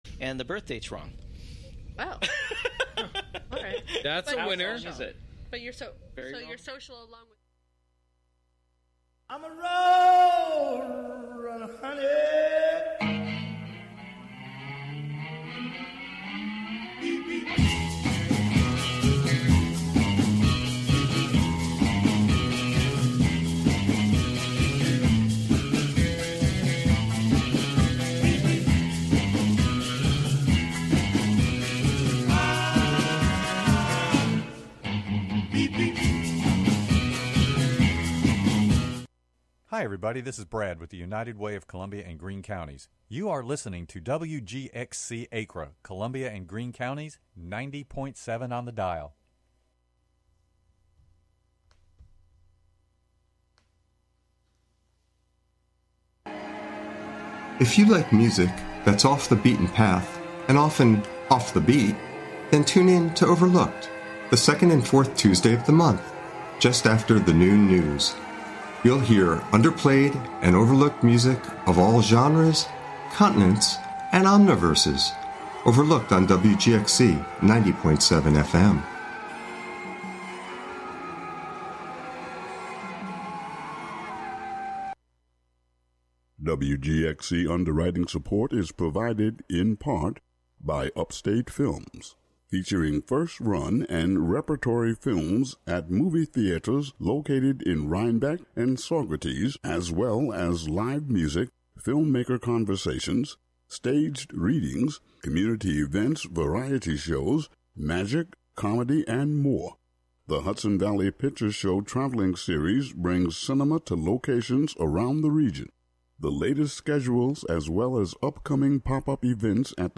Calling all our lovers in for another night of your favorite love songs with your favorite hosts. Plus, we tackle a voicemail from a lovely listener who needs some advice.
The Love Motel is a monthly late-night radio romance talk show with love songs, relationship advice, and personals for all the lovers in the upper Hudson Valley.